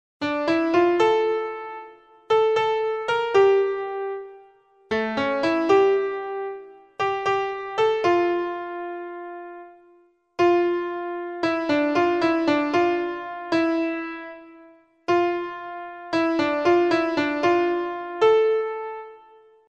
( фрагмент ), mp3, 307 КБ_ _ _  _ _ _D-1